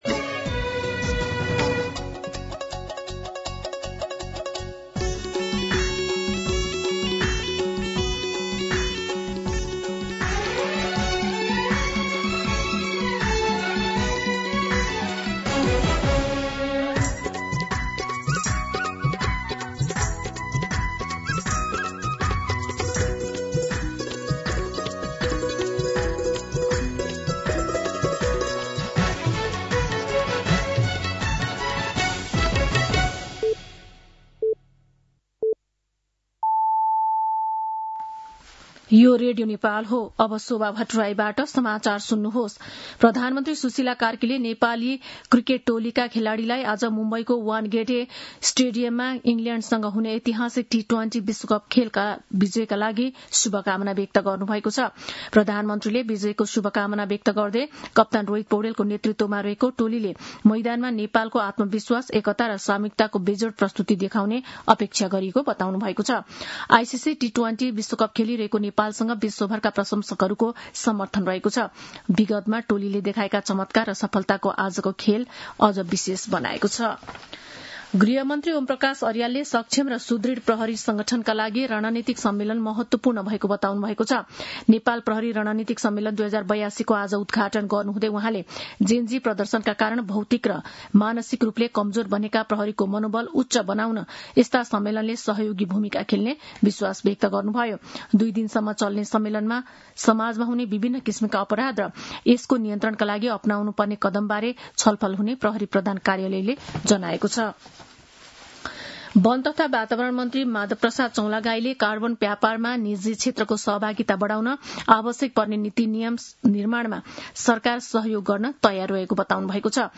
दिउँसो ४ बजेको नेपाली समाचार : २५ माघ , २०८२
4pm-News-25.mp3